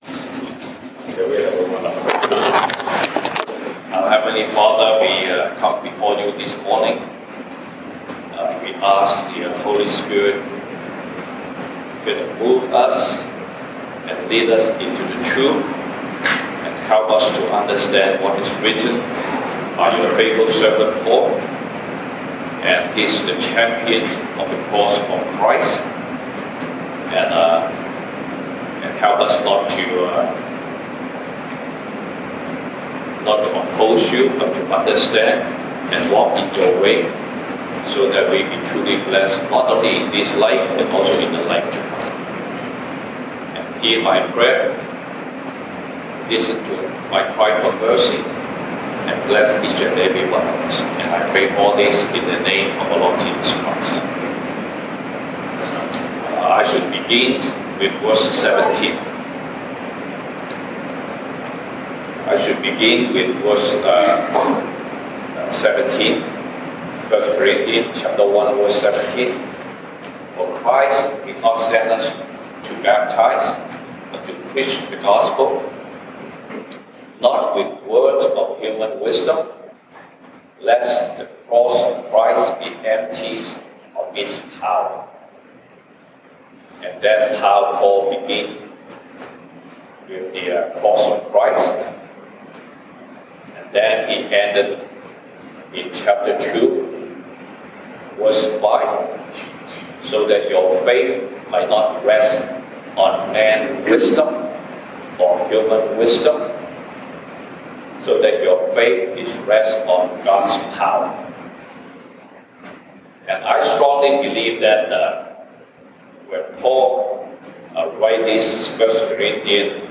西堂證道 (英語) Sunday Service English: The message of God’s mystery
Service Type: 西堂證道 (英語) Sunday Service English Topics: The message of God's mystery